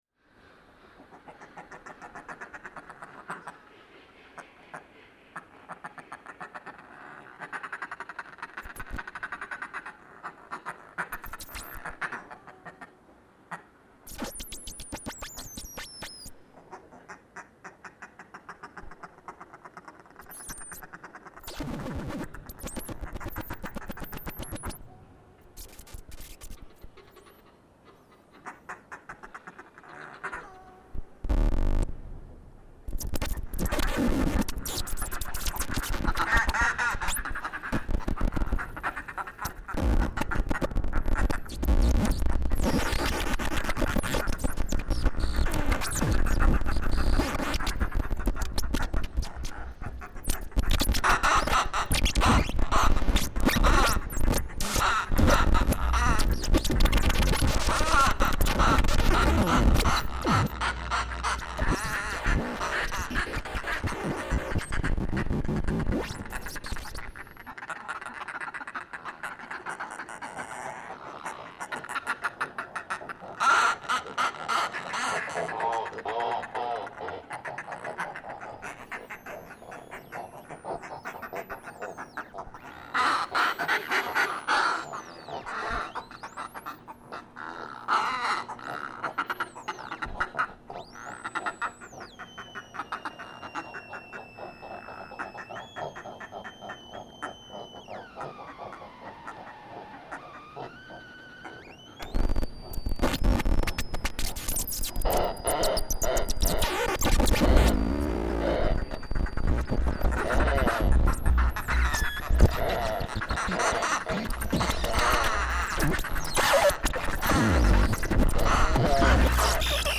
The percussive, squawking electronic sounds have some similarities to the bird vocalizations, but are generally different and sometimes drown the birds out. I performed the electronics in response to the bird recordings.